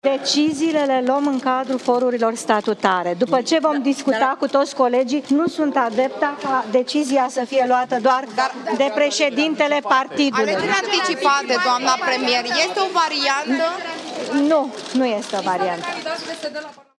Viorica Dăncilă – așteptată de jurnaliști pe holurile Parlamentului, a spus, din mers, că nu e de acord cu alegerile anticipate: